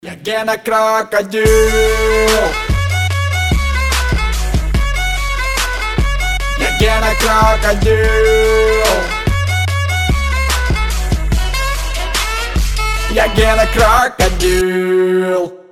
веселые
смешные
пародии
Забавный именной рингтон для Гены и Геннадия